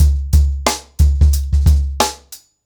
TrackBack-90BPM.77.wav